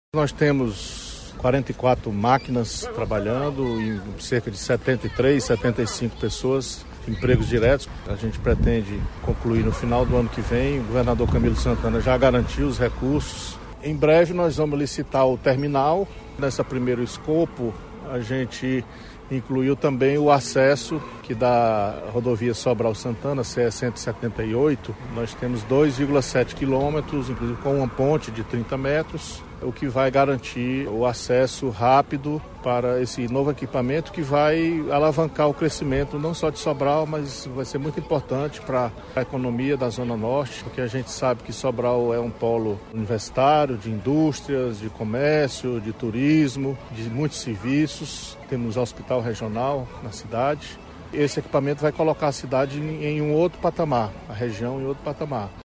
Para o secretário da Seinfra, Lúcio Gomes, a obra do Novo Aeroporto vai alavancar ainda mais o desenvolvimento do município. Ele fala do andamento da obra.